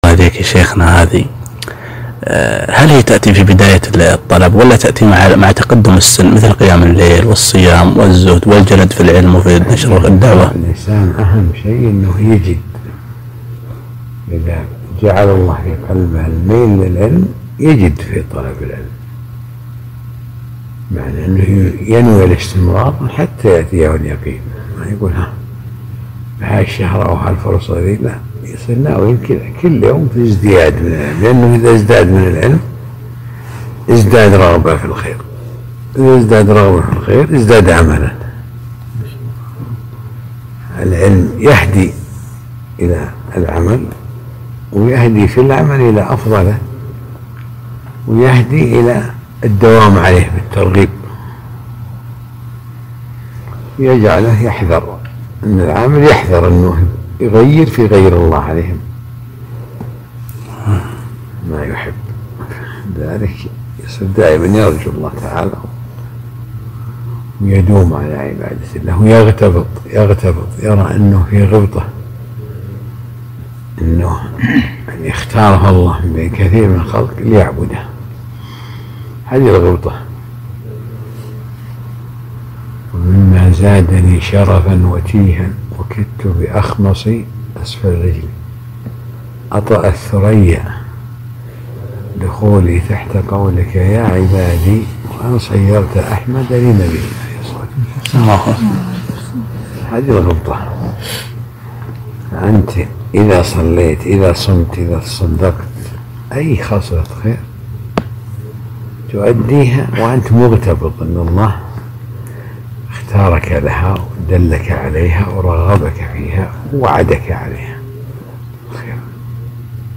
لقاء ماتع نافع وتوجيهات نافعة مع الأجوبة على الأسئلة